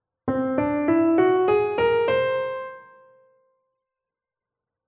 Ganztonschritte
Auf der Tastatur (von C beginnend) wären es dann drei weiße und anschließend drei schwarze Tasten:
Dabei fällt auf, dass es insgesamt nur sechs Töne (einer weniger als bei der Dur-Tonleiter) sind.
TonleiternGanzton.mp3